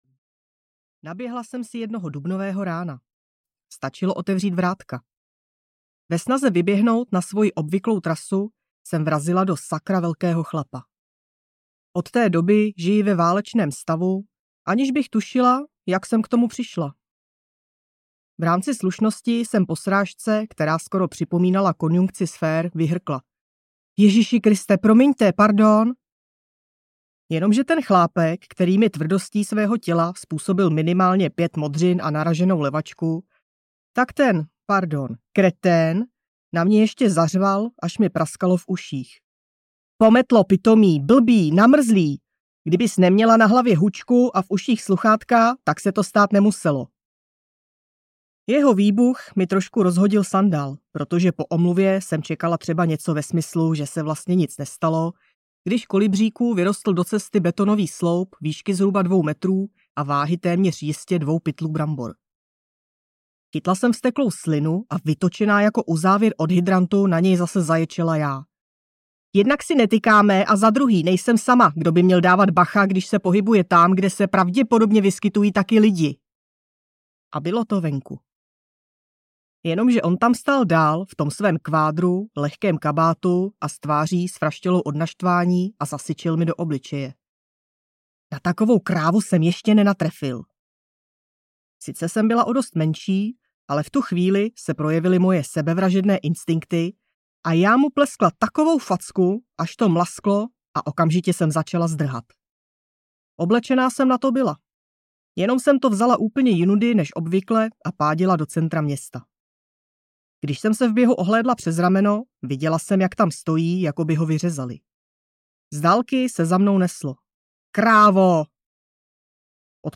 Audio knihaKráva a kretén
Ukázka z knihy